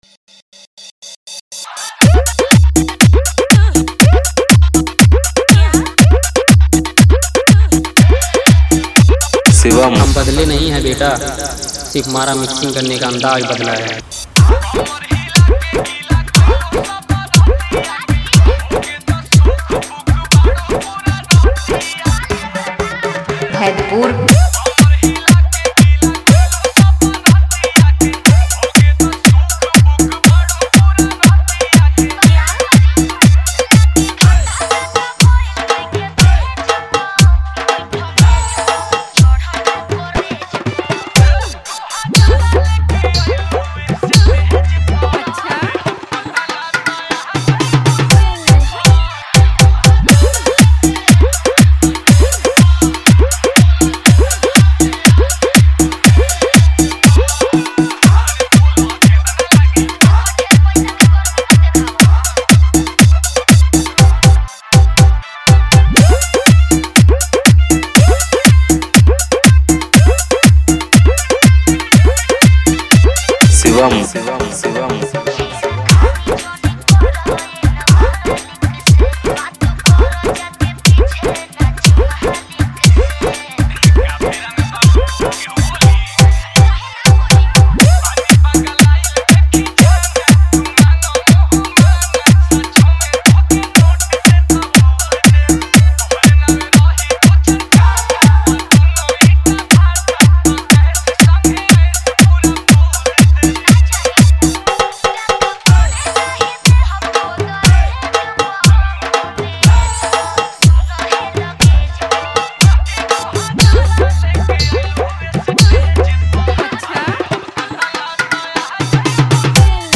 Bhojpuri Dj Songs